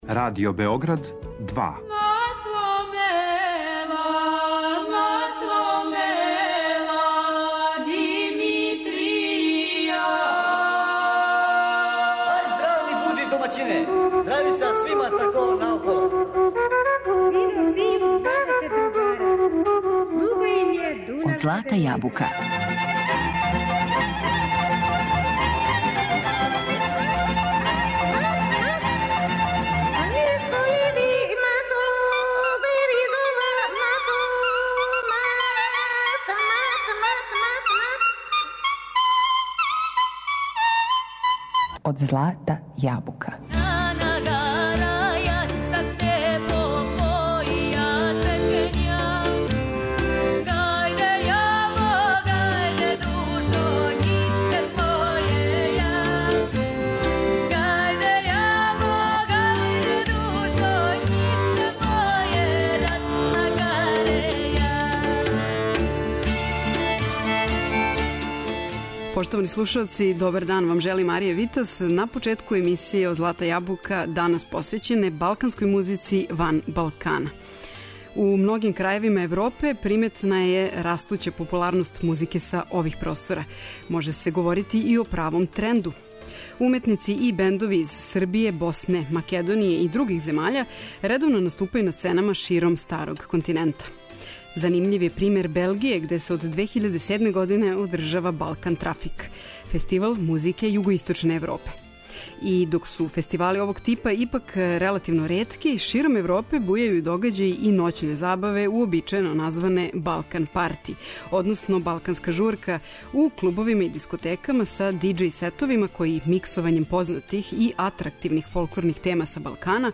Представљамо балканску музику у извођењу група из земаља ван балканског подручја.
Слушамо управо интерпретације таквих бендова који су у неким случајевима састављени искључиво од уметника небалканског порекла, али још чешће је реч о комбинацији страних музичара са онима пореклом из Југоисточне Европе.